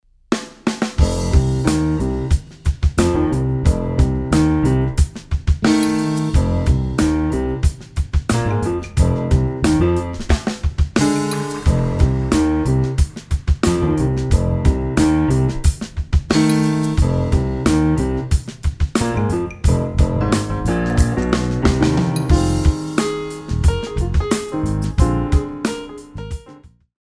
8 bar intro
allegro moderato
Funky-Rock / Contemporary